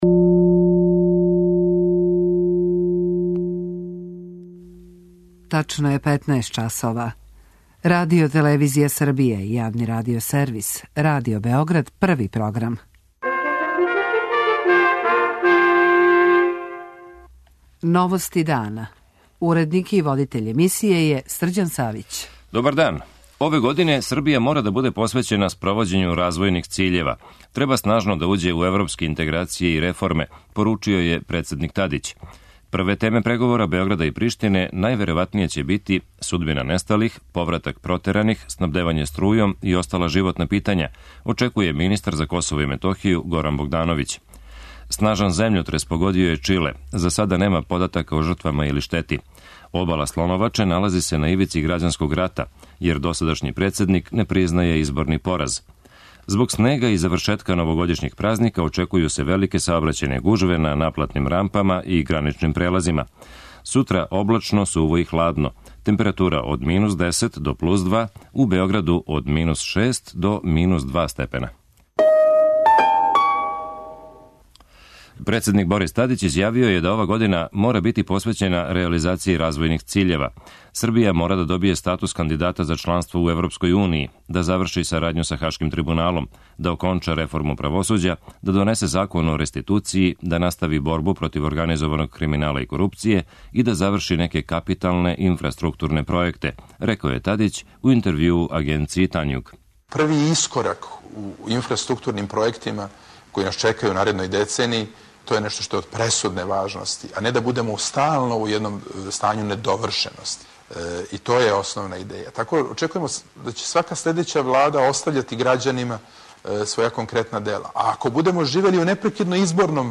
Држави и њеним грађанима предстоји много озбиљног посла у 2011., јер стање на многим пољима није добро, али ипак од ове године треба очекивати позитивне помаке, каже председник Тадић у интервјуу Танјугу, који слушамо у Новостима дана.
Чућемо и делове из интервјуа који је нашој станици дао министар за Косово и Метохију Горан Богдановић.